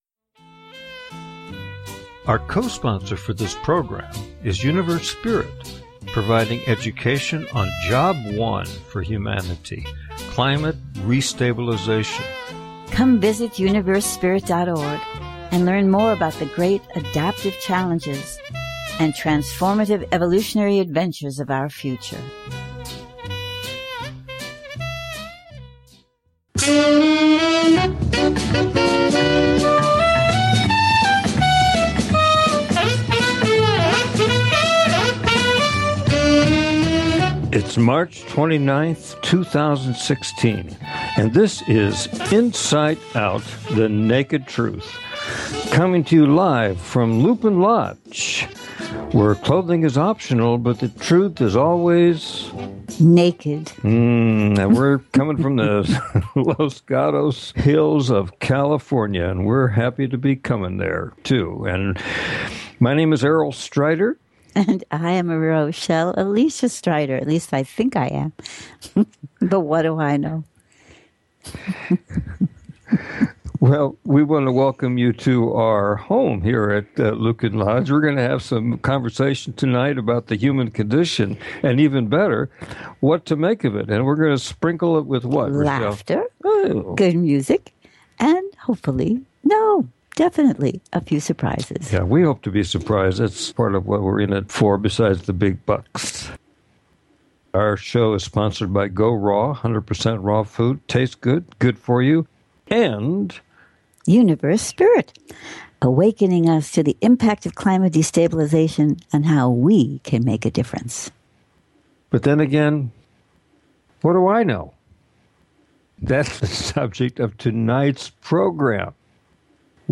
You can also expect some great music, and who knows, you may come away from the conversation knowing something you didn't know that you knew.
Talk Show